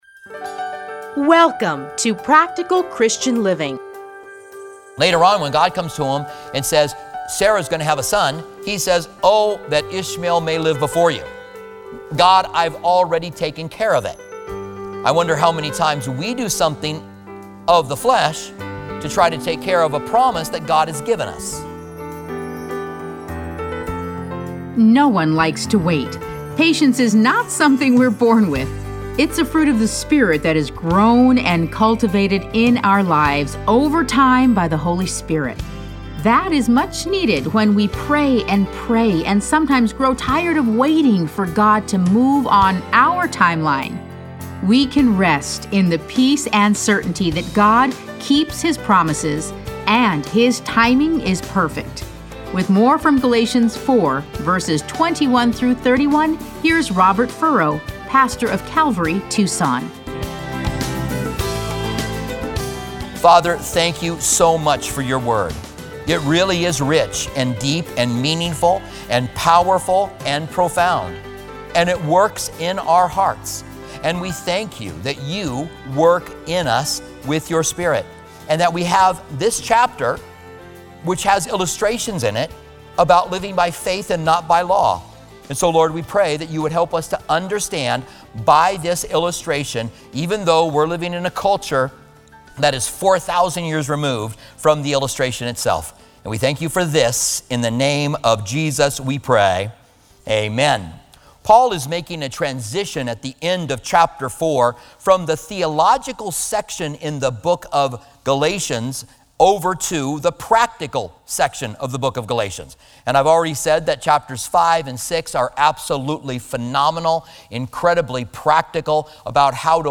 Listen to a teaching from Galatians 4:21-31.